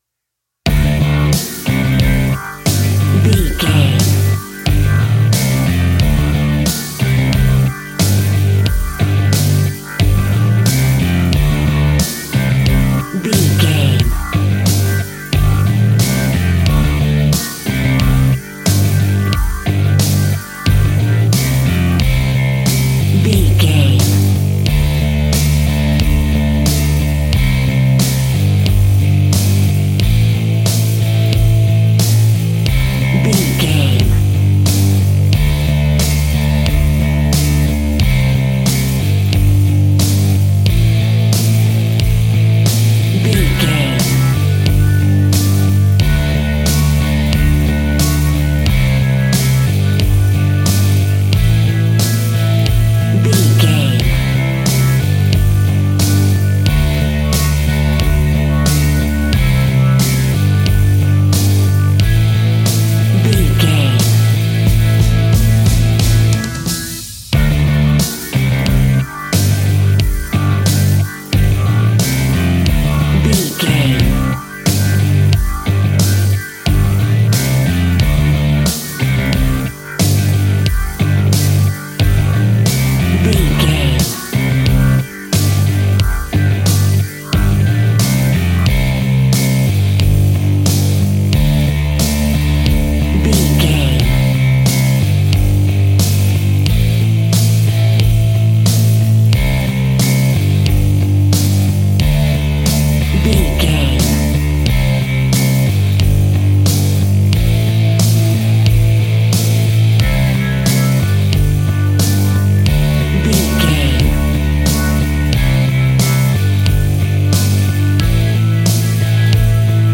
Fast paced
Mixolydian
D
hard rock
blues rock
distortion
rock instrumentals
Rock Bass
heavy drums
distorted guitars
hammond organ